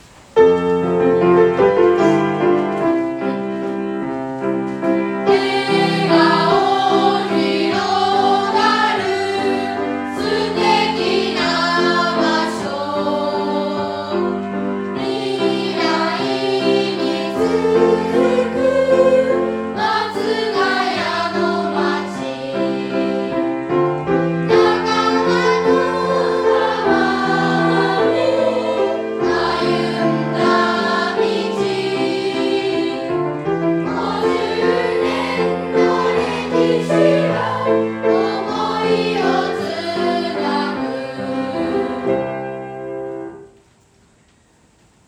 50周年ソング ６年生バージョン